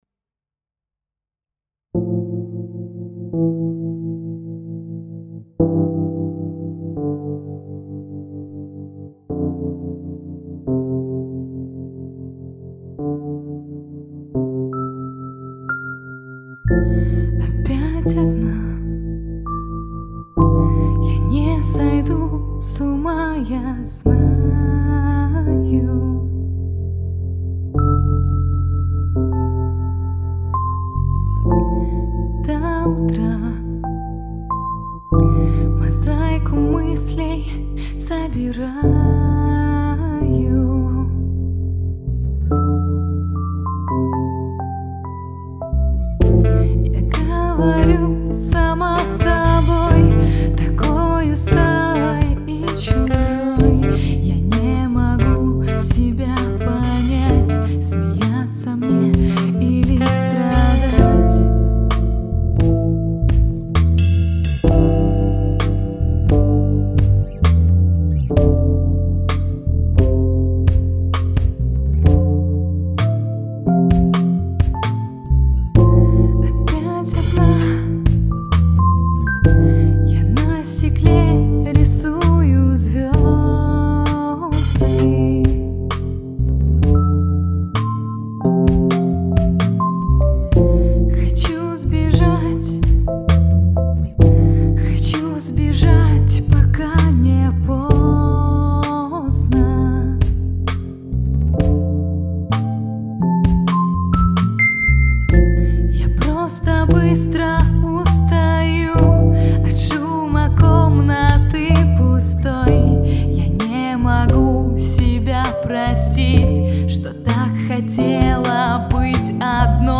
гитара, бас-гитара
вокал
ударные
клавиши